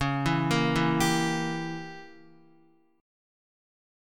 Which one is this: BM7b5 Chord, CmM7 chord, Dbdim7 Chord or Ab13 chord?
Dbdim7 Chord